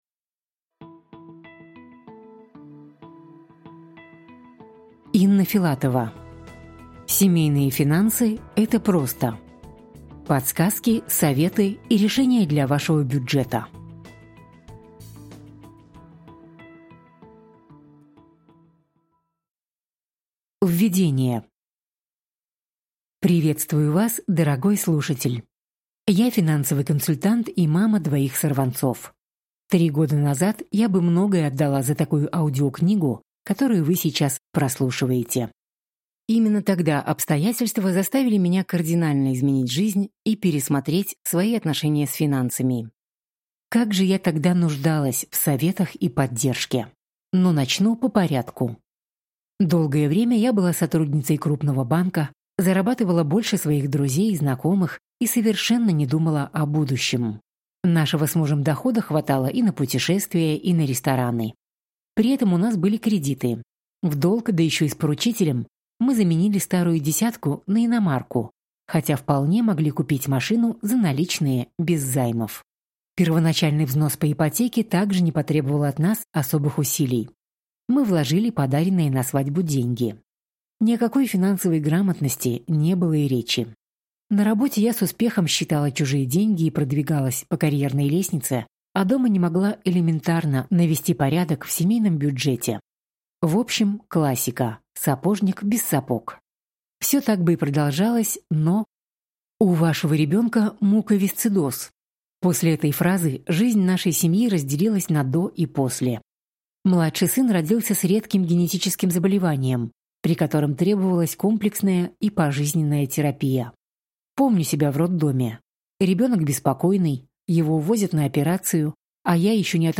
Аудиокнига Семейные финансы – это просто: Подсказки, советы и решения для вашего бюджета | Библиотека аудиокниг